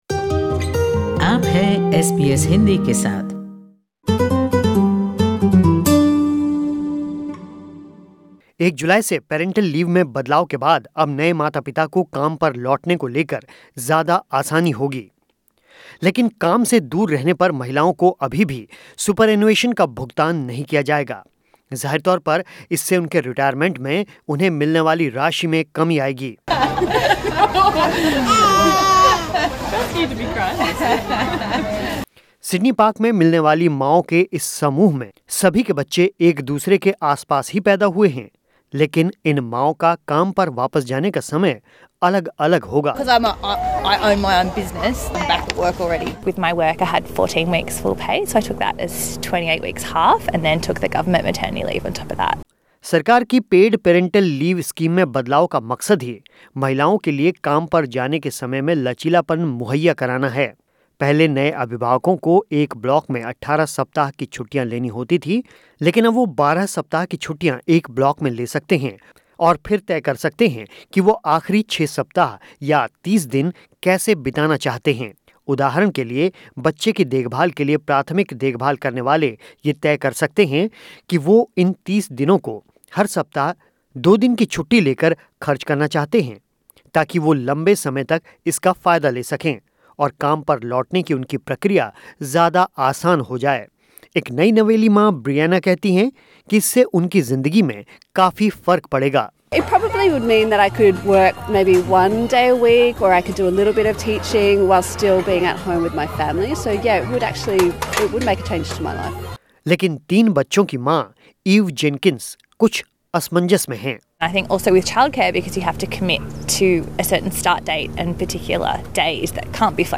सिडनी पार्क में मिलने वाली मांओं के समूह में से एक महिला कहती हैं कि वो खुद का व्यवसाय करती हैं इसलिए वो बच्चे के पैदा होने के कुछ समय बाद ही काम पर वापसी कर चुकी हैं.